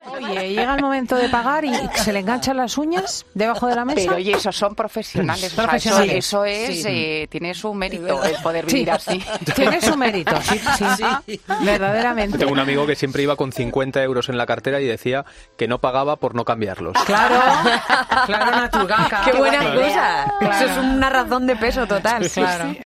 Una historia que se ha contado en 'Fin de Semana' y que ha provocado la carcajada de todo el equipo
"Siempre iba con 50 euros en la cartera y siempre decía que no pagaba por no cambiarlos" contaba, mientras el resto de miembros del equipo, incluida Cristina López Schlichting, estallaban en una carcajada.